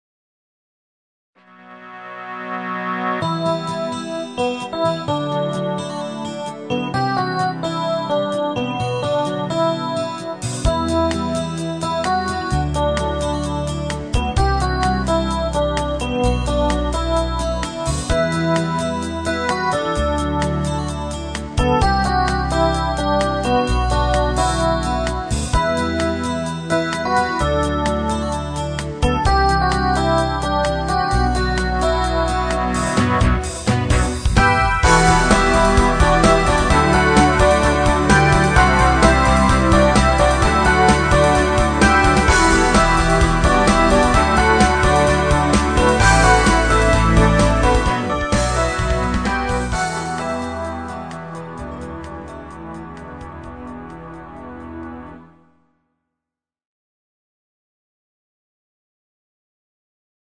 Genre(s): Schlager  Deutschpop  |  Rhythmus-Style: Rockbeat